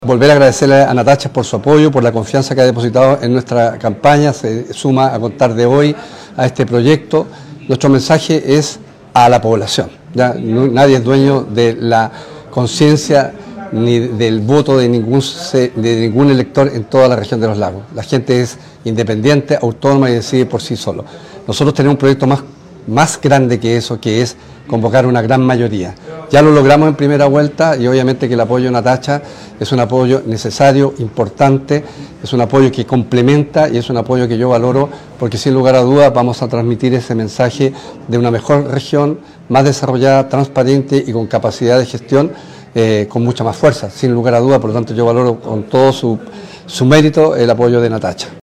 Esta confianza en la decisión de la gente lo que nos da fuerza para continuar nuestro trabajo con transparencia y honestidad”, puntualizó finalmente durante un punto de prensa realizado este lunes en la ciudad de Osorno.